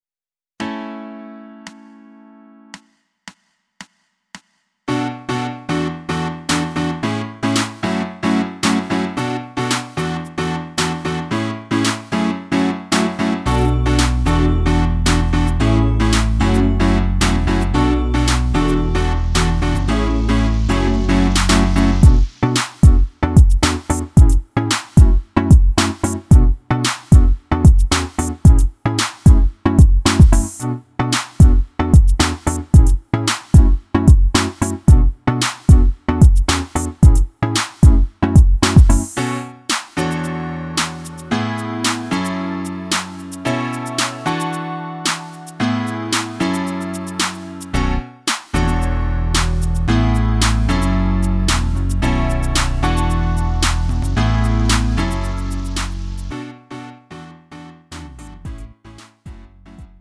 음정 -1키 3:51
장르 가요 구분 Lite MR
Lite MR은 저렴한 가격에 간단한 연습이나 취미용으로 활용할 수 있는 가벼운 반주입니다.